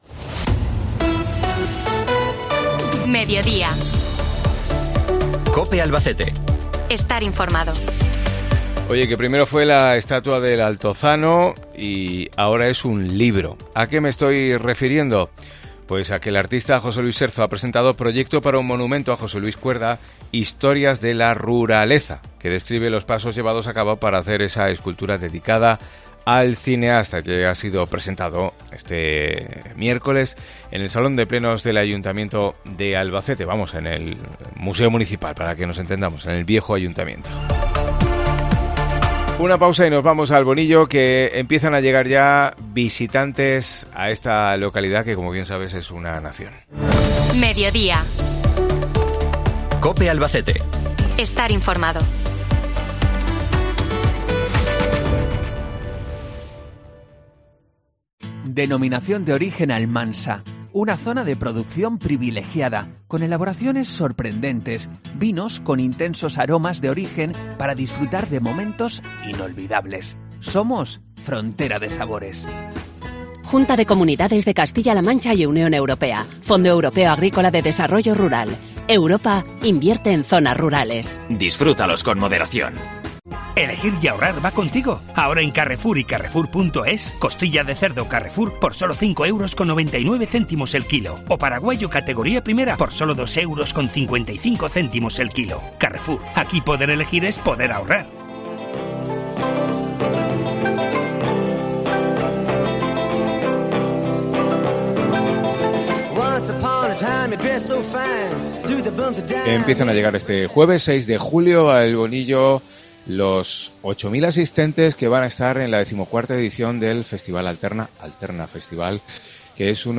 Entrevista con el concejal de El Bonillo, Jesús Castellanos, sobre el Alterna Festival